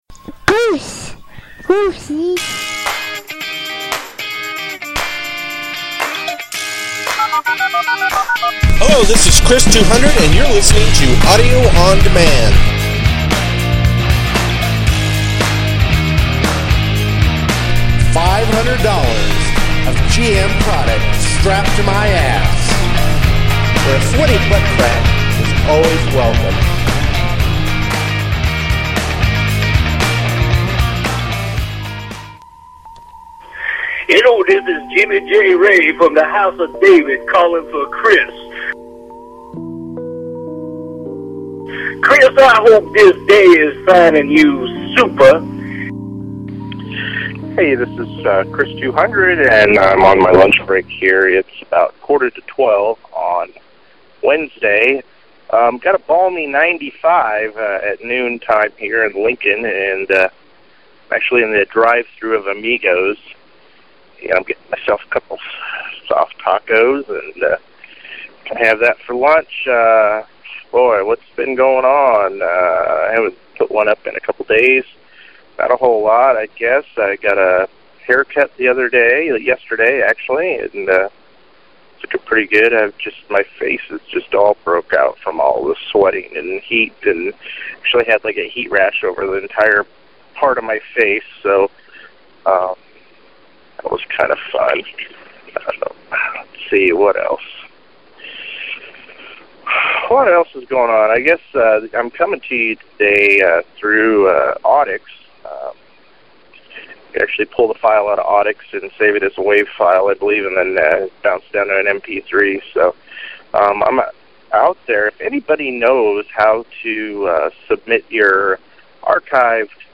Checking in on the voice mail and a song from Dark Proggy.